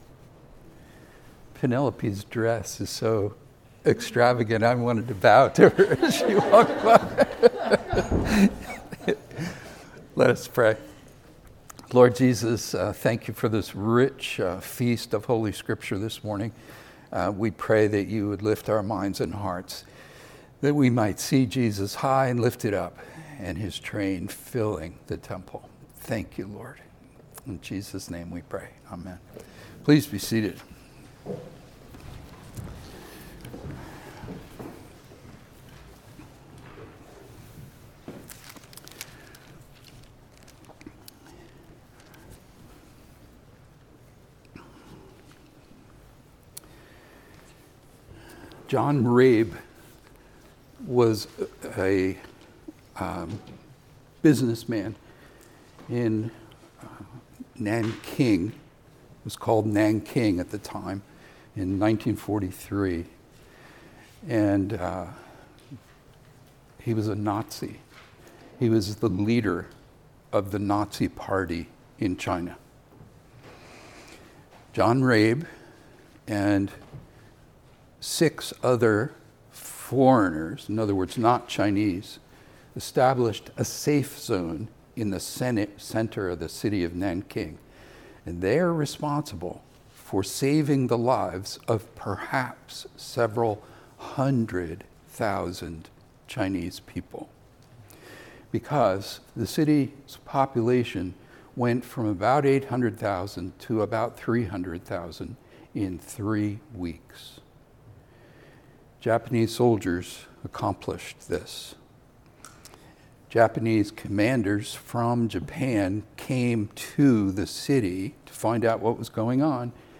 Sermons | Anglican Church of the Ascension